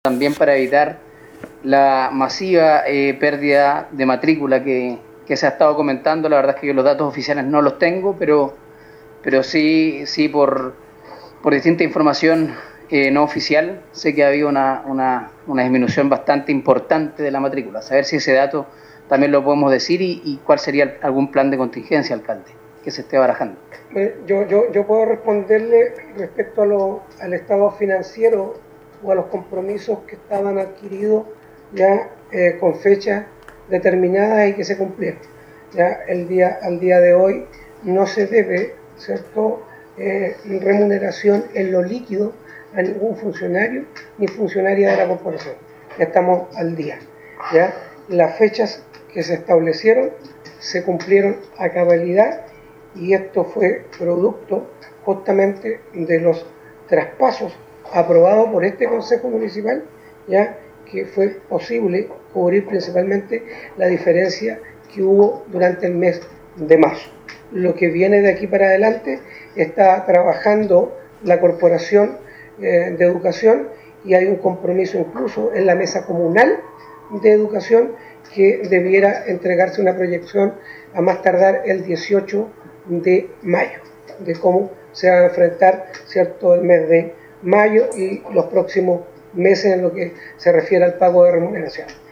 En la ocasión, el concejal Samuel Mandiola consultó al alcalde de la comuna por estos antecedentes, a lo que respondió en primer término el alcalde Carlos Gómez que efectivamente en los últimos días se pudo pagar el dinero restante que se adeudaba del mes de marzo pasado.
16-CONCEJAL-SAMUEL-MANDIOLA.mp3